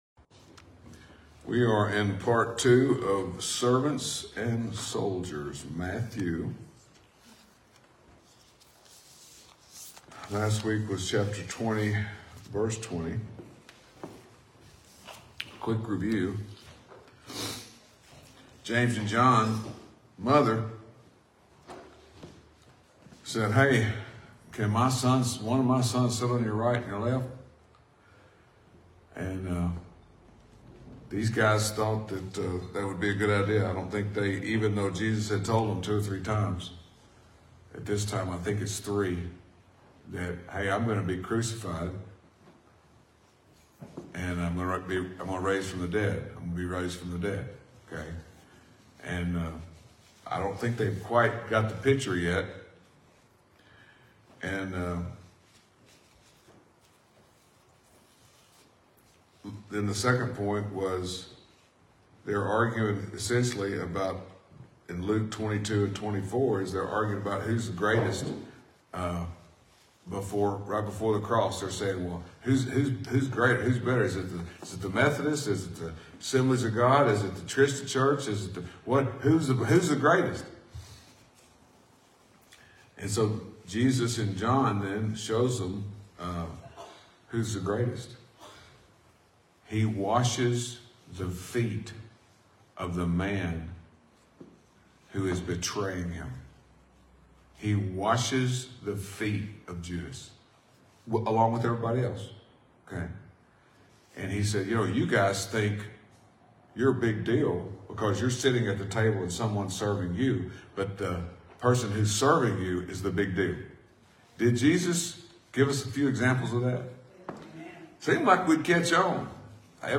Sunday Sermon 4-28-24